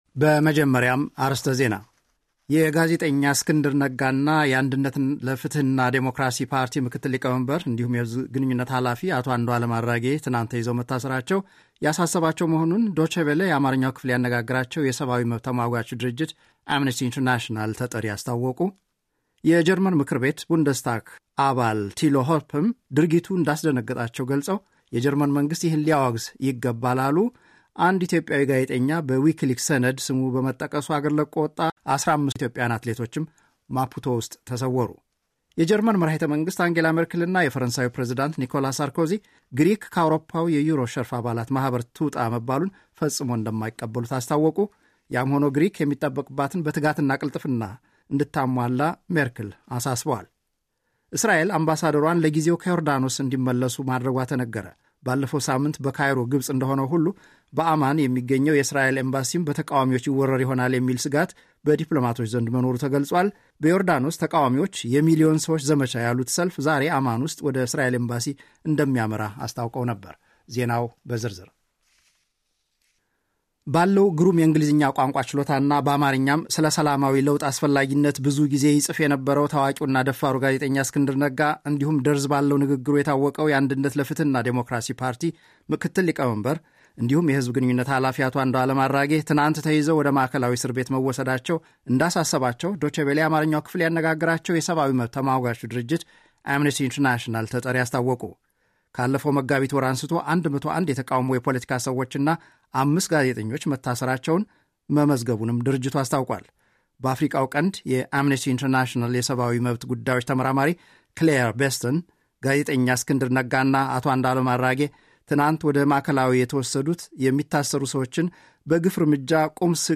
ጀርመን ራዲዮ ዜናዎች – Sep 15, 2011